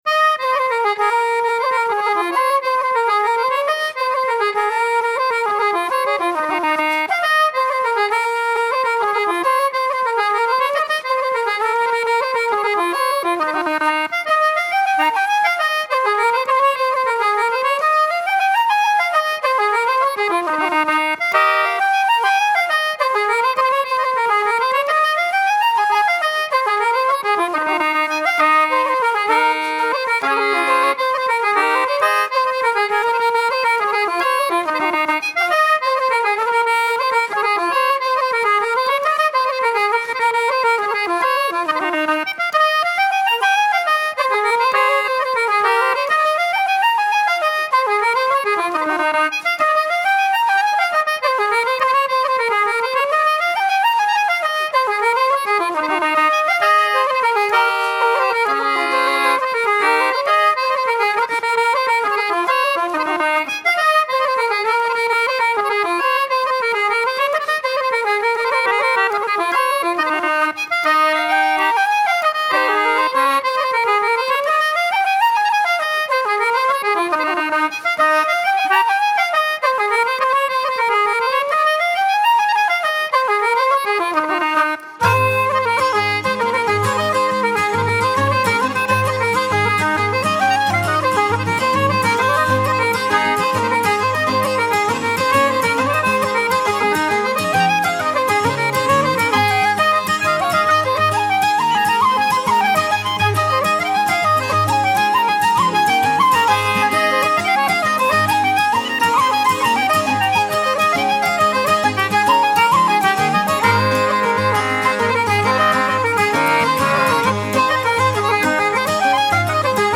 My musical acquaintances might think that this post is all about Kerry slides – lively tunes which get aired sometimes at our session